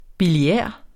Udtale [ biliˈεˀɐ̯ ]